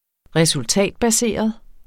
Udtale [ -baˌseˀʌð ]